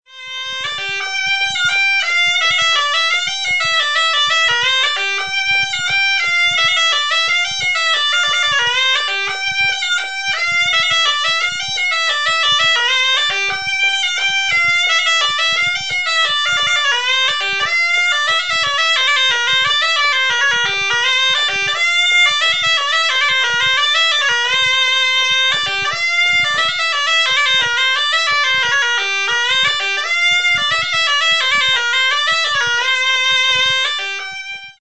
Cabrettes et Cabrettaïres le site Internet officiel de l'association de musique traditionnelle auvergnate
Les bourrées